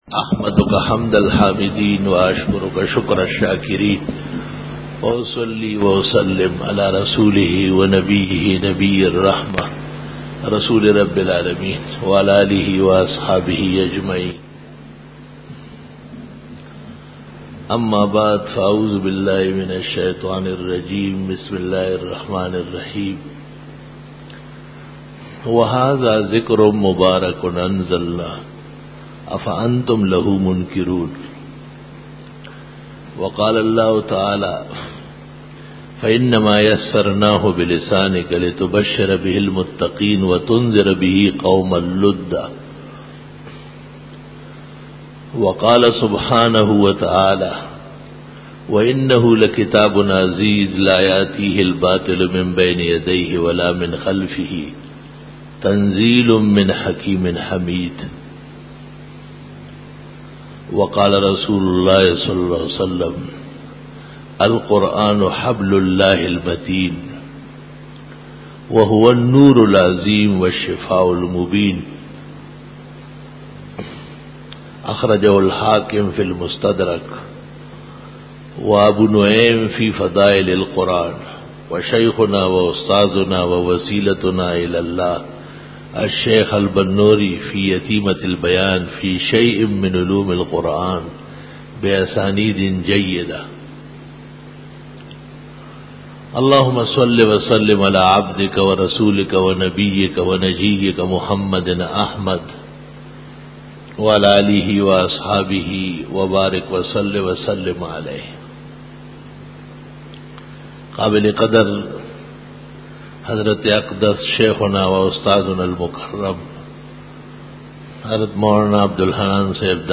افتتاحی بیان